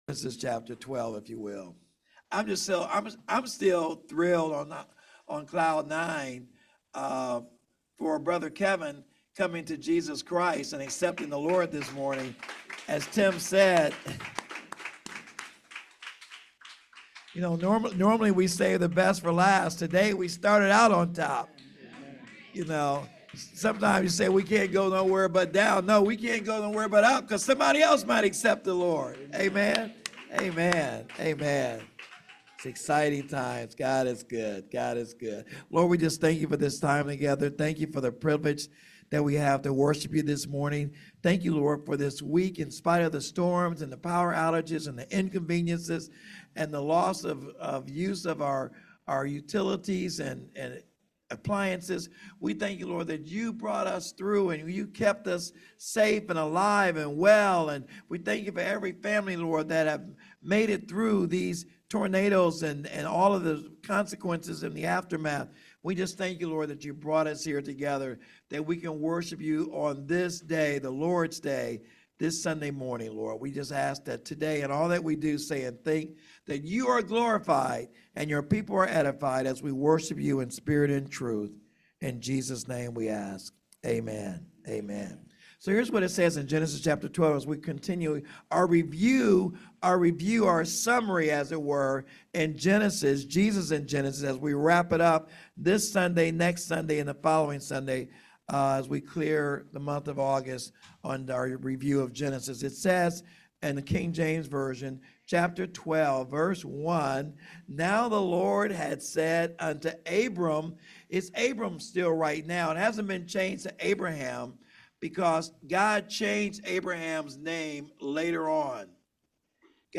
Sermon Handout